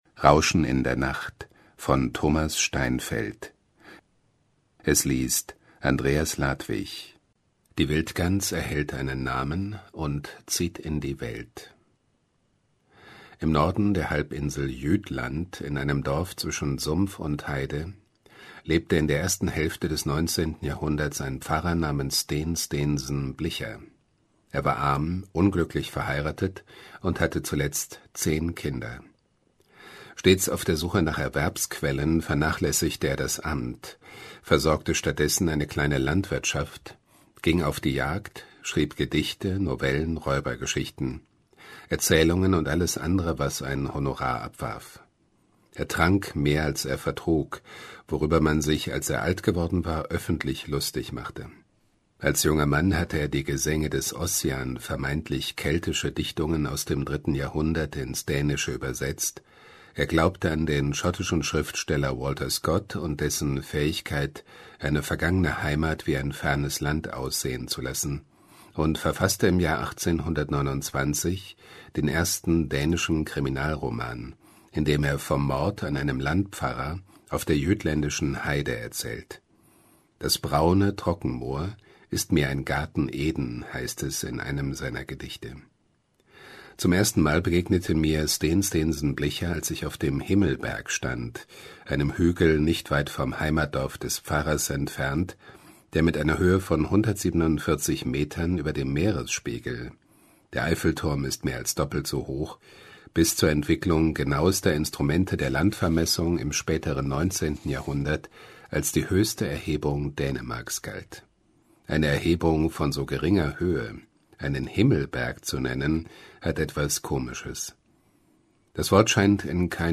liest für diese Geschichte um die Wildgans, die kulturell enorme Bedeutung im 20. Jahrhundert erlangte: